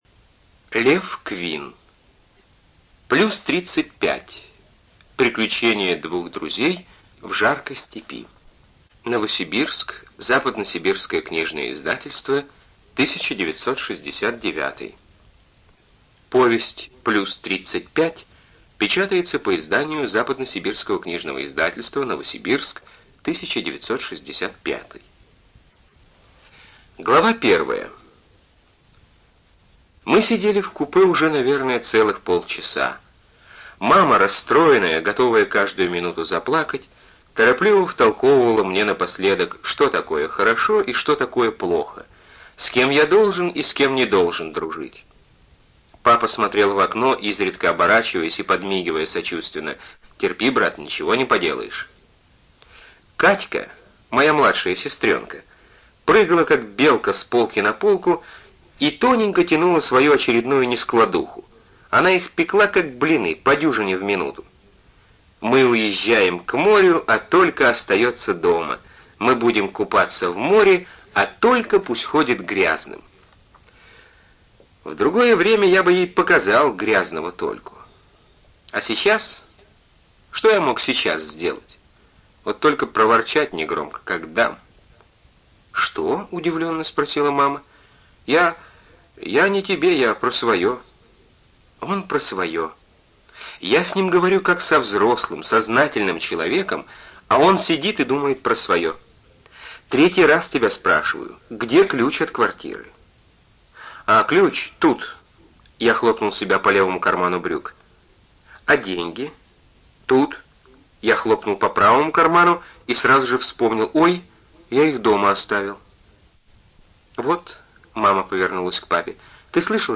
ЖанрПриключения, Детская литература
Студия звукозаписиЛогосвос